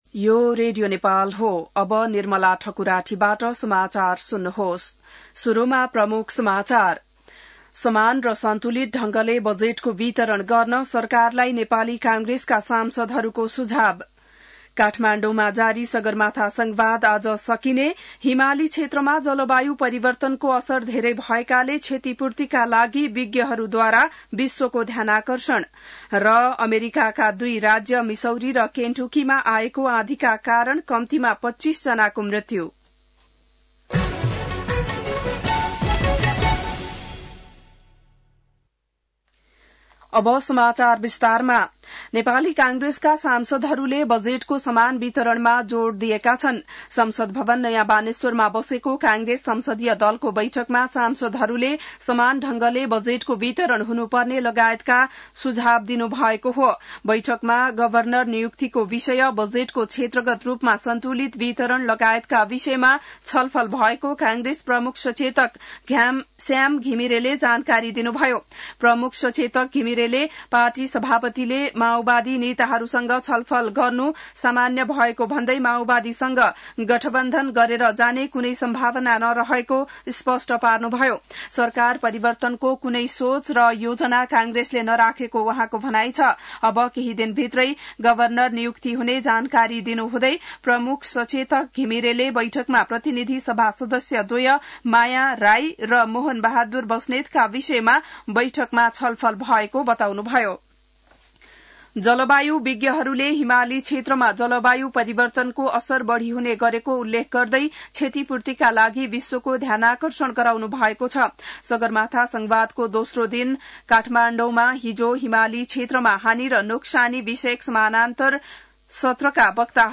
बिहान ९ बजेको नेपाली समाचार : ४ जेठ , २०८२